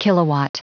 Prononciation du mot kilowatt en anglais (fichier audio)